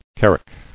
[car·ack]